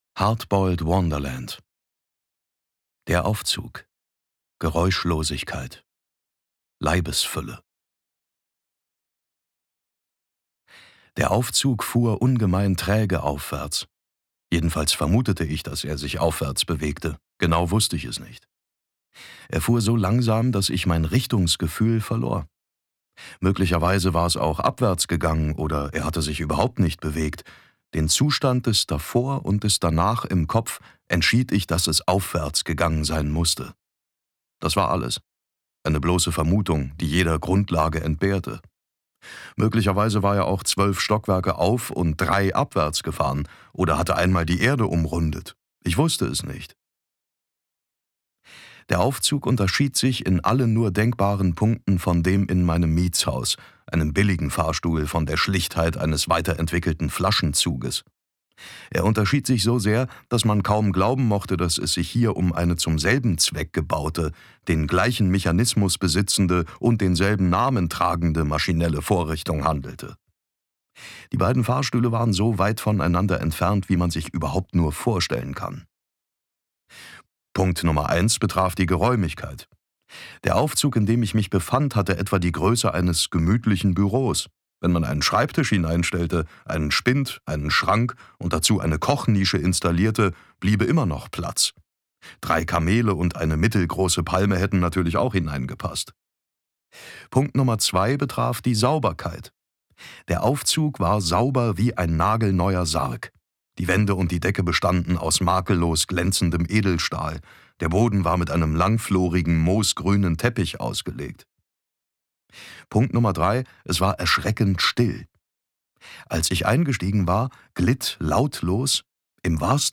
David Nathan (Sprecher)